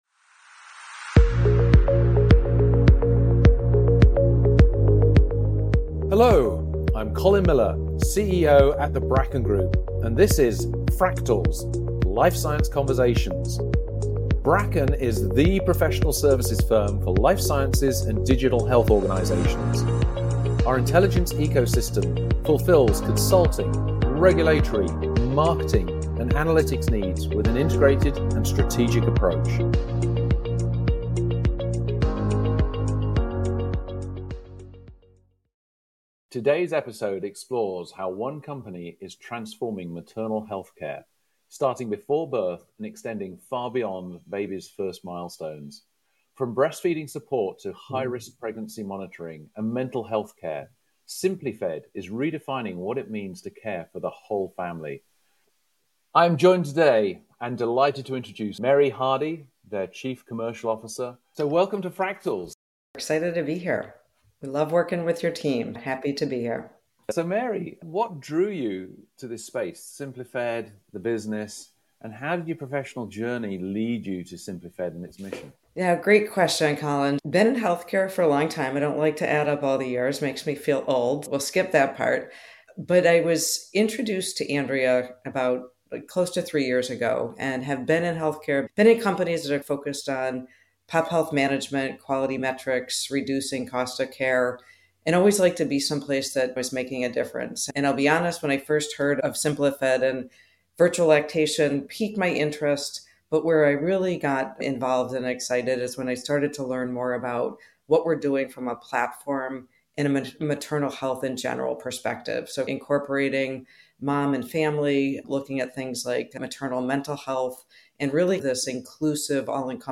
Insightful, informative, and warmly good-humored, Fractals features life sciences leaders sharing wisdom, career highs and lows, and compelling stories from the intersections of clinical development, health care, and technology.